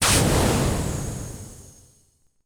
Pressurize.wav